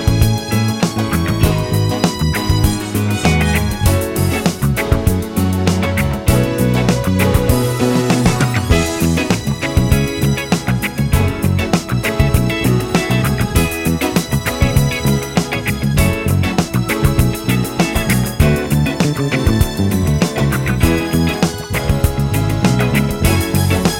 no Backing Vocals Disco 3:18 Buy £1.50